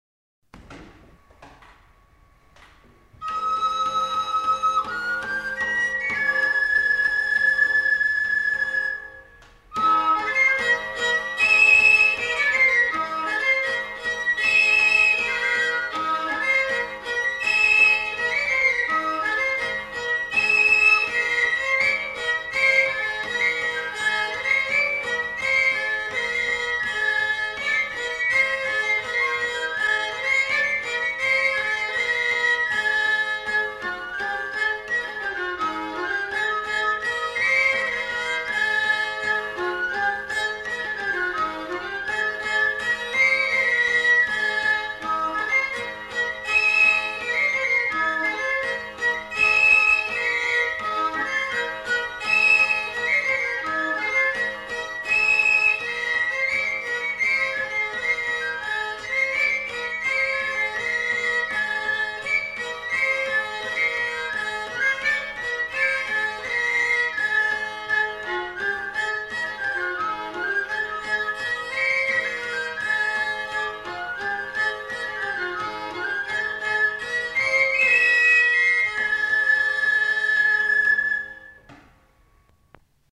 Aire culturelle : Béarn
Lieu : Bielle
Genre : morceau instrumental
Instrument de musique : violon ; flûte à trois trous ; tambourin à cordes
Danse : branlo airejan